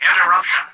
"Interruption" excerpt of the reversed speech found in the Halo 3 Terminals.